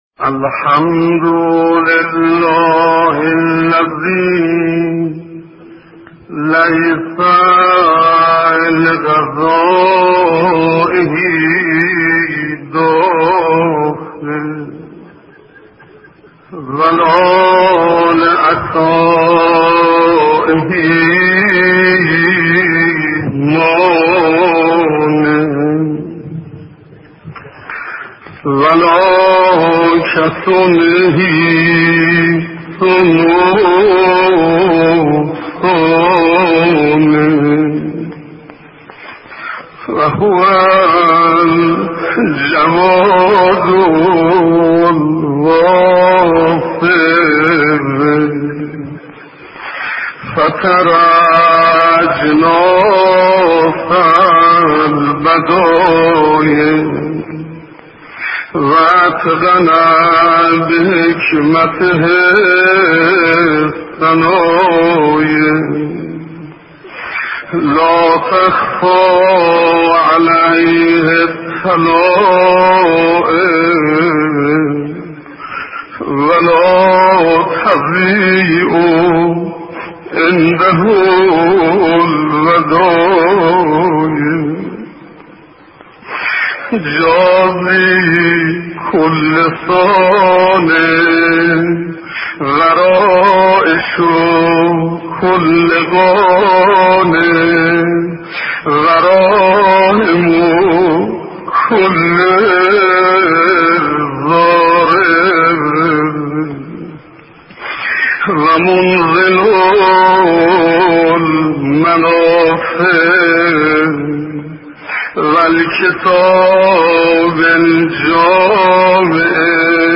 صوت و متن کامل دعای عرفه با نوای شیخ حسین انصاریان